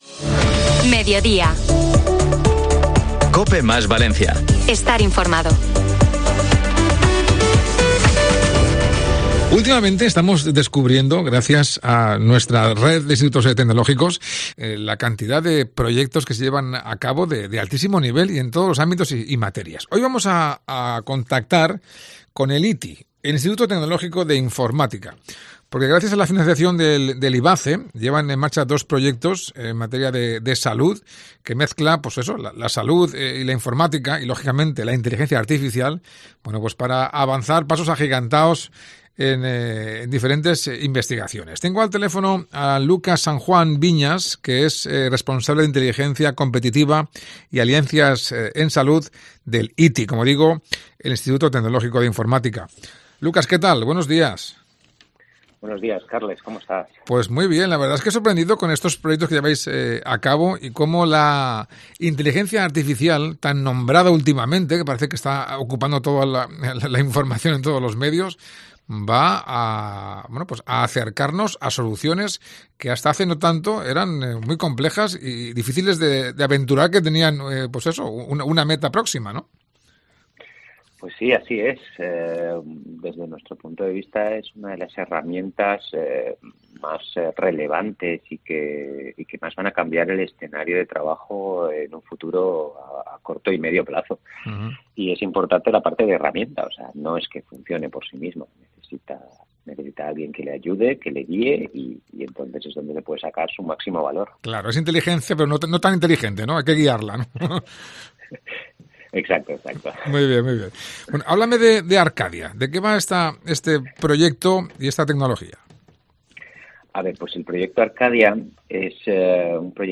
Son dos de los proyectos que el ITI lleva a cabo gracias a la financiación de IVACE y que ponen la última tecnología al servicio de la investigación científica y la salud. Así lo explicaba en el programa Mediodía de Cope Valencia